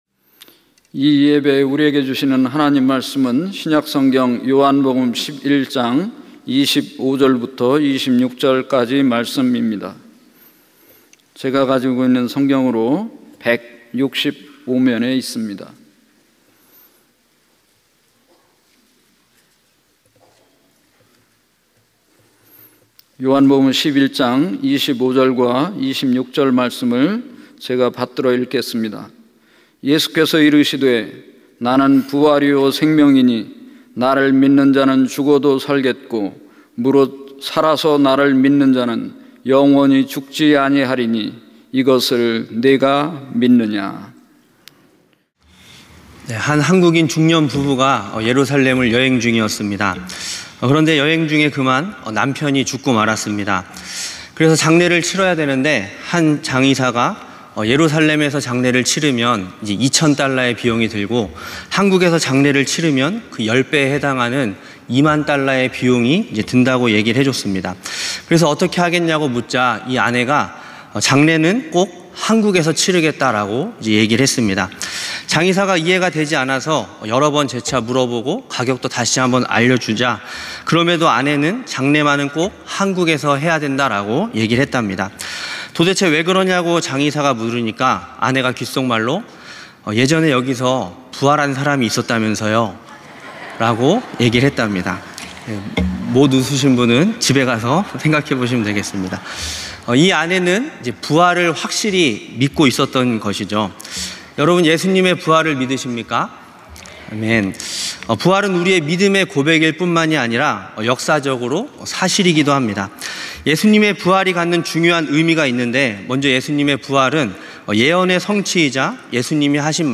찬양예배 - 부활을 사는 사람들